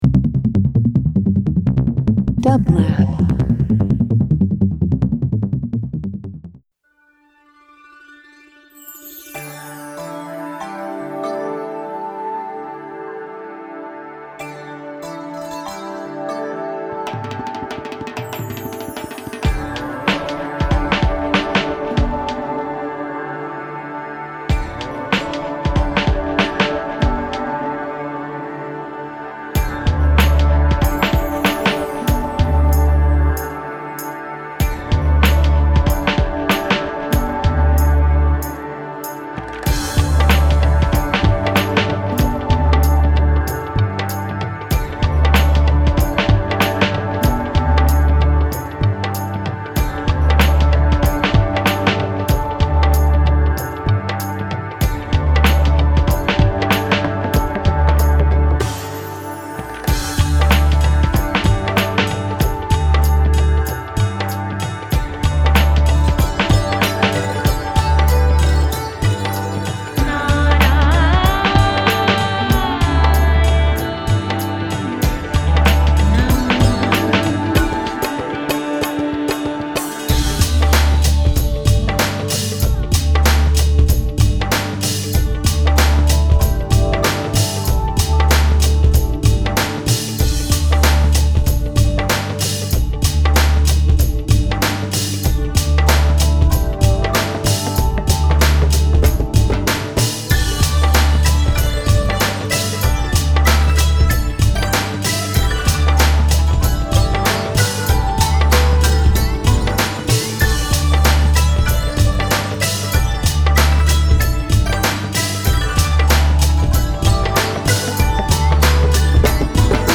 Indian Jazz Traditional